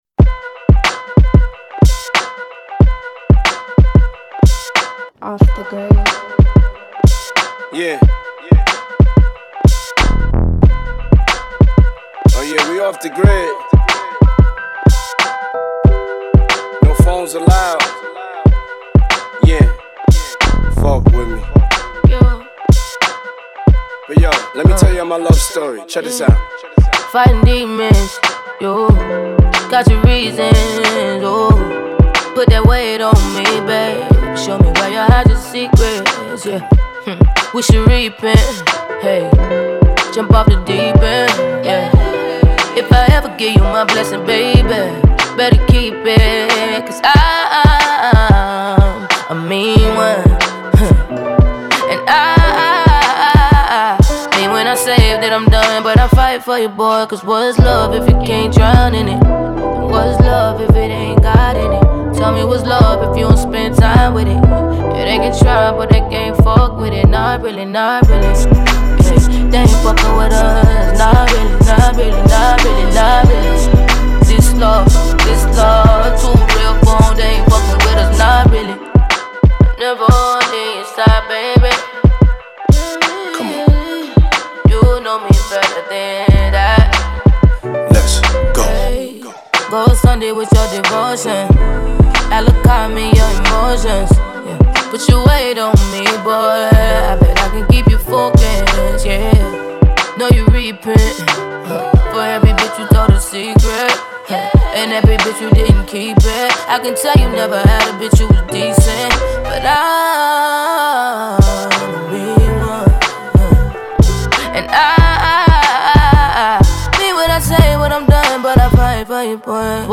Genre : Soul, Funk, R&B